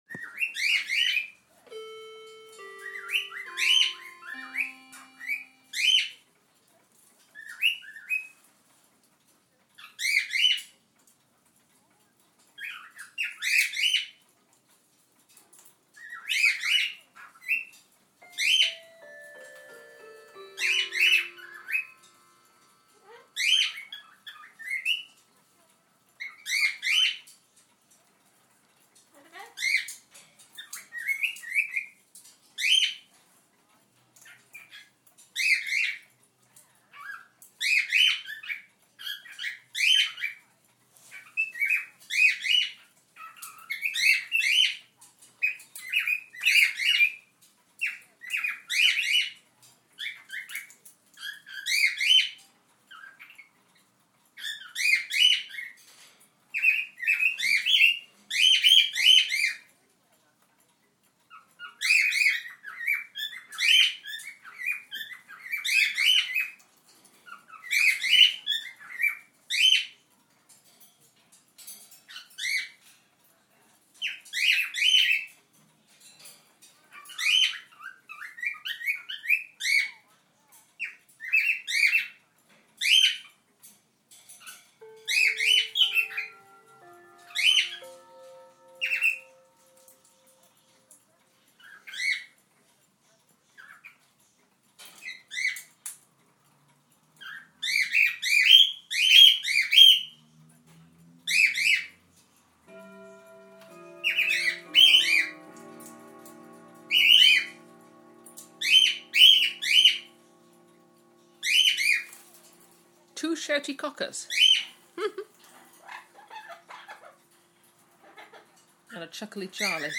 Happy cockatiels.
Cockatiel boo.